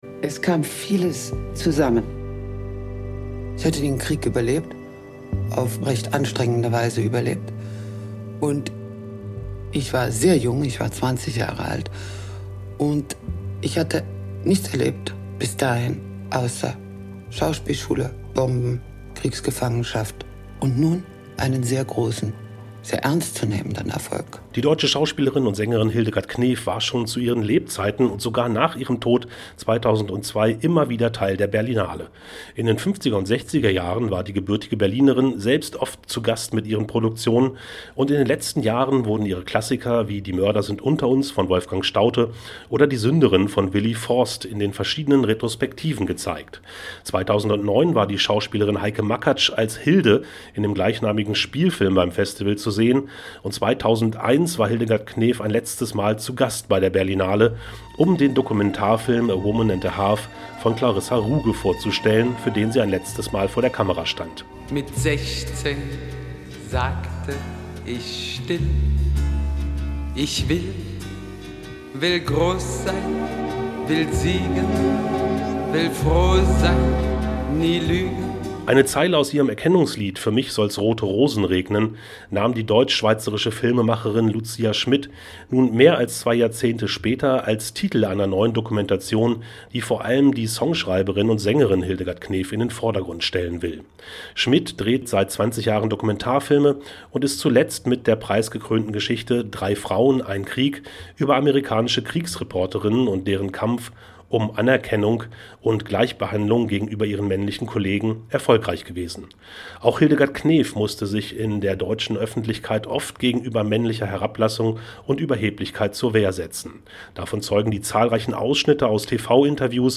(Radiobeitrag zu „Ich will alles.Hildegard Knef“)